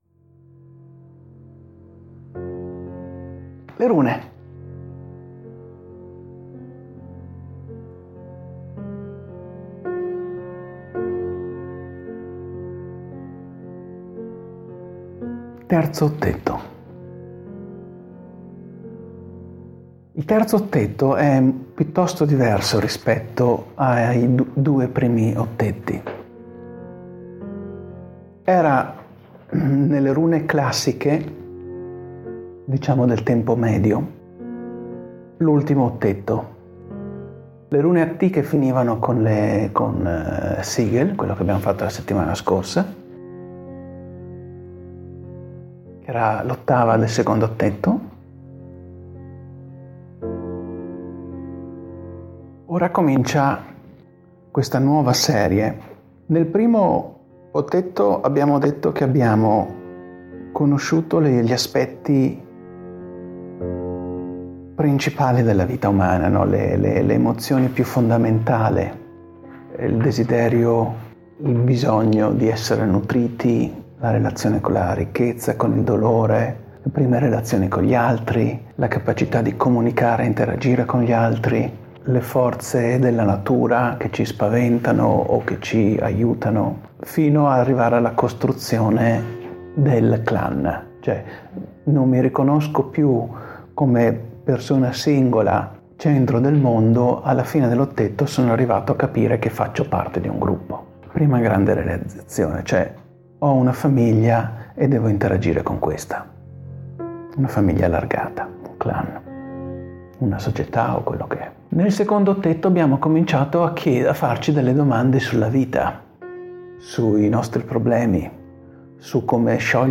Ecco: Questo è un estratto da una lezione di meditazione con le Rune.